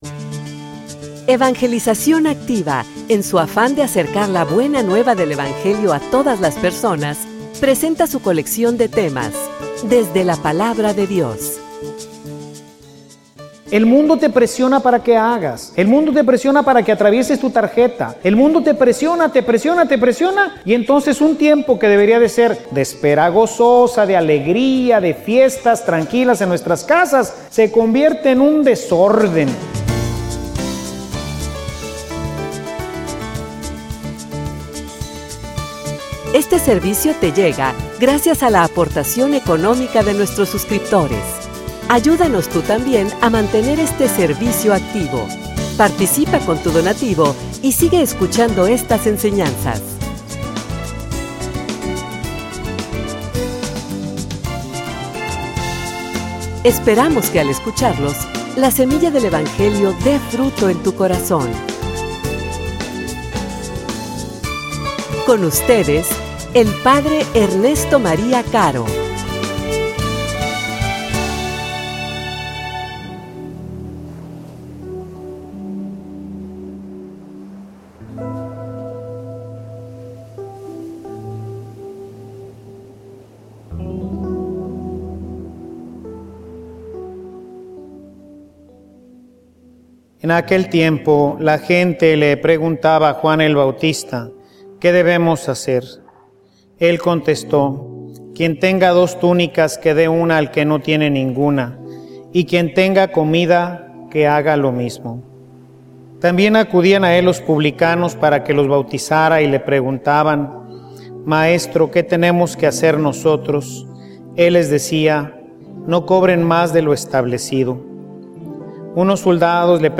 homilia_esperar_serenamente.mp3